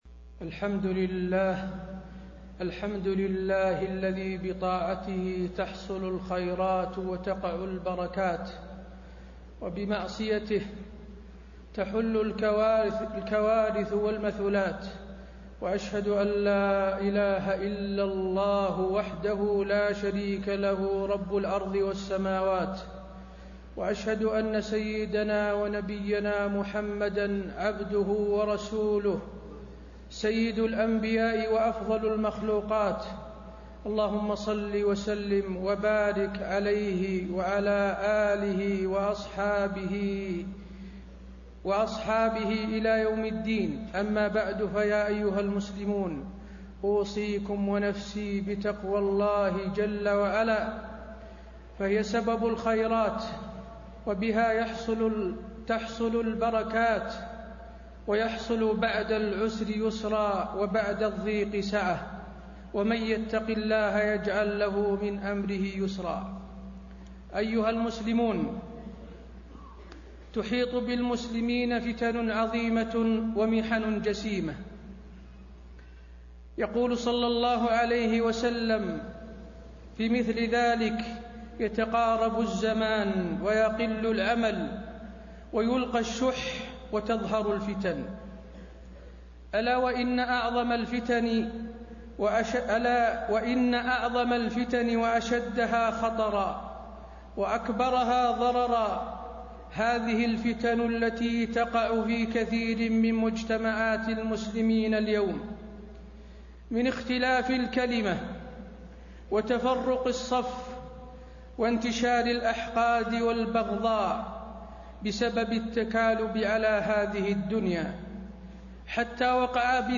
تاريخ النشر ٢٦ ربيع الثاني ١٤٣٤ هـ المكان: المسجد النبوي الشيخ: فضيلة الشيخ د. حسين بن عبدالعزيز آل الشيخ فضيلة الشيخ د. حسين بن عبدالعزيز آل الشيخ نصائح للراعي والرعية